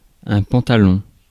Ääntäminen
IPA : /ˈpænts/